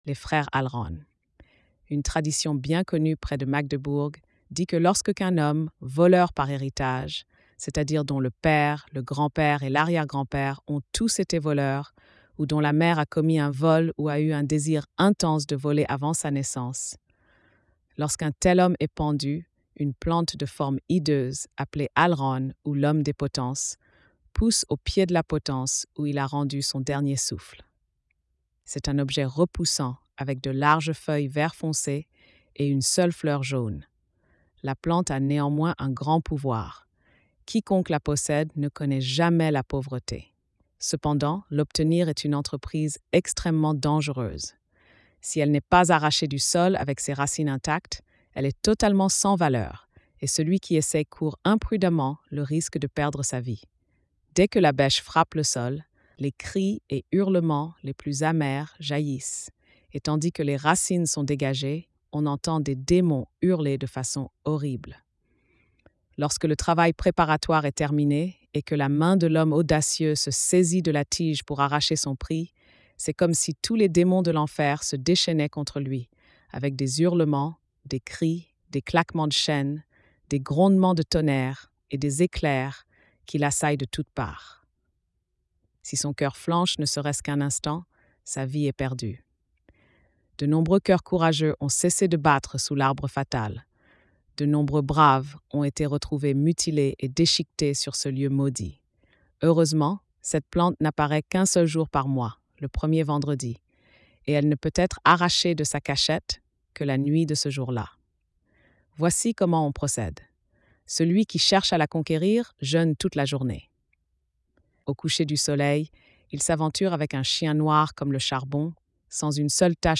Conte de Grimm
🎧 Lecture audio générée par IA